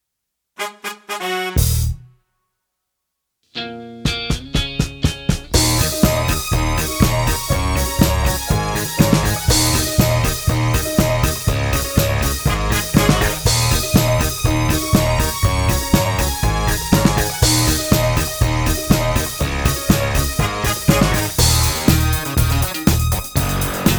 no Backing Vocals Comedy/Novelty 3:46 Buy £1.50